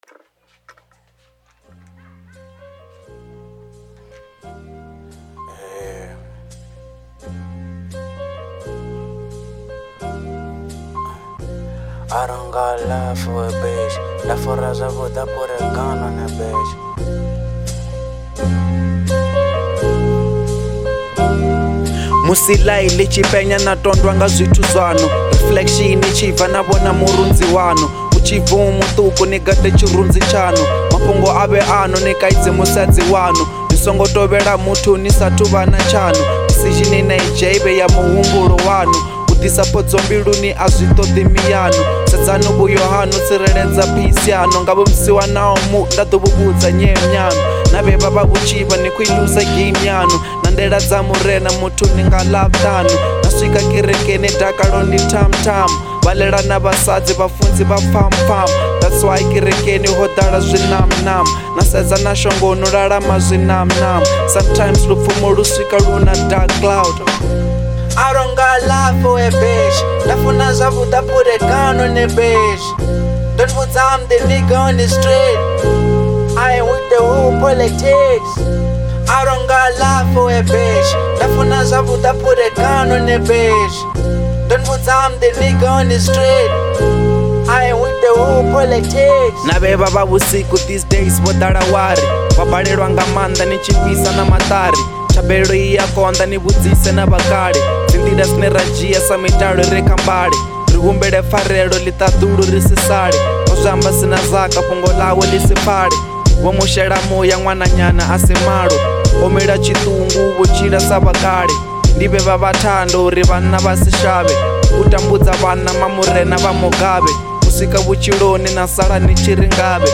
02:46 Genre : Venrap Size